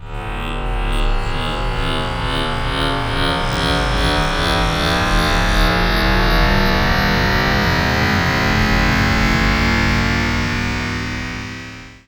SCIFI_Up_02_mono.wav